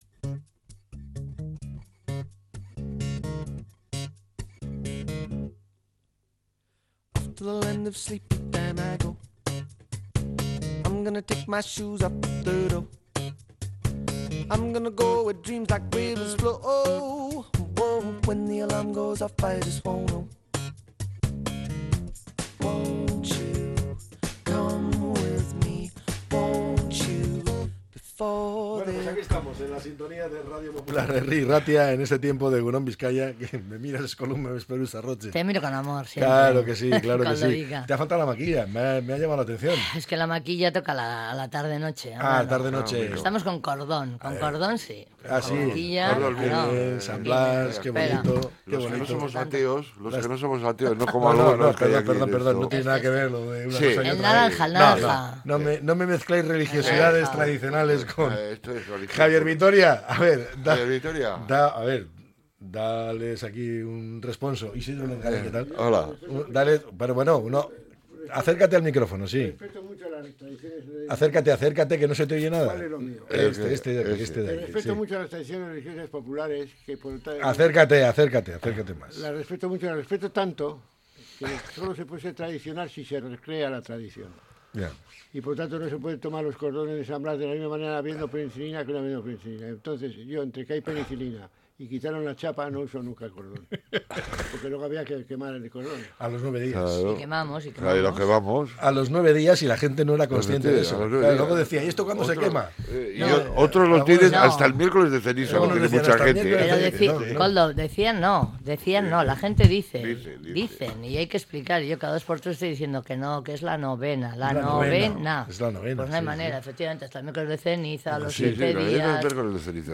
La tertulia 04-02-25.